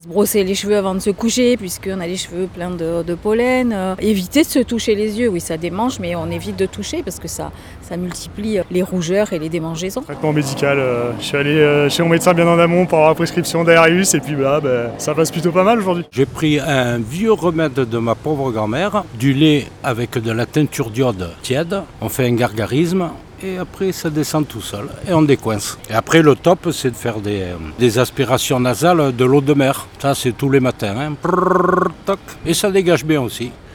Certains ont des solutions face à cela, et l'expliquent au micro